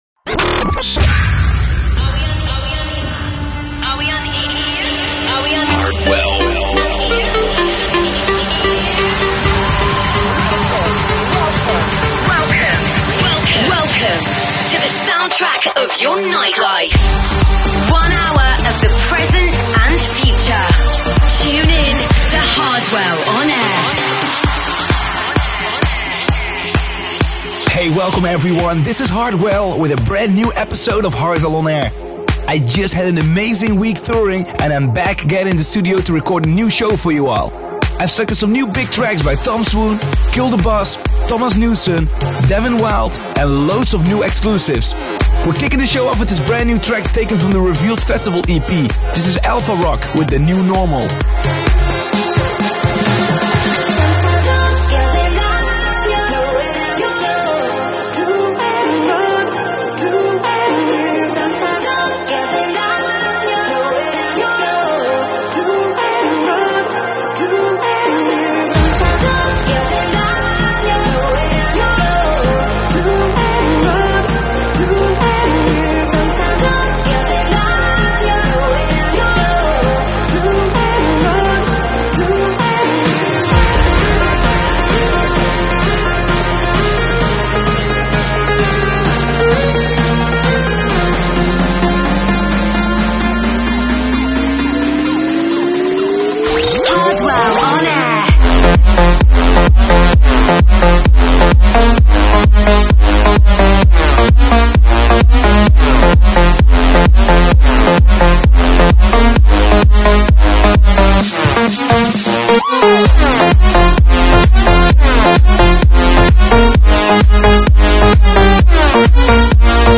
latest floorfillers